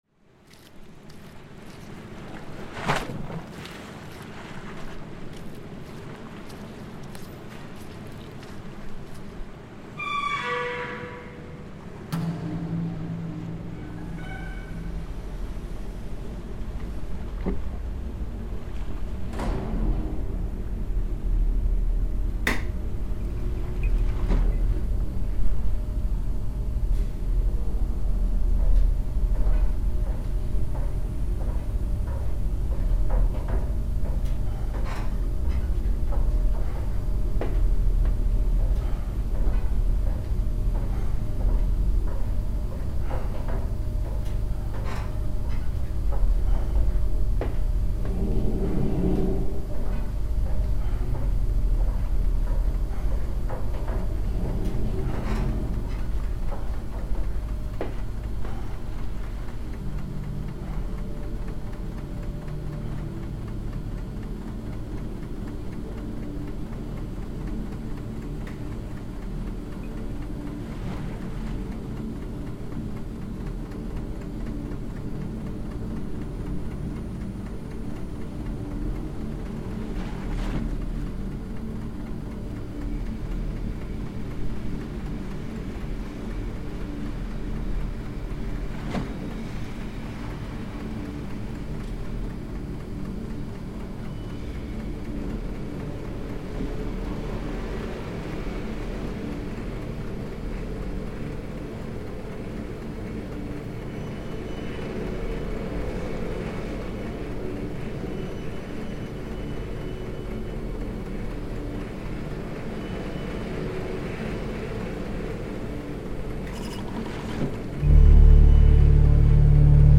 Waves at Mosteiros reimagined by Cities and Memory.
For this composition we've imagined that there is a lighthouse at Mosteiros in the Azores - there is, in reality, no such thing. In our imagined soundscape, the lighthouse keeper walks up to the door of the lighthouse on a foggy morning, waves crashing either side of him. The huge door opens and closes behind him, and he turns on a buzzing electric light.
He pulls the foghorn lever twice, with the horn erupting inside the lighthouse.
As well as the waves recording, the following samples were used: Inside the lighthouse on Flat Holm Island, recorded by Cities and Memory. An English country church door closes, recorded by Cities and Memory.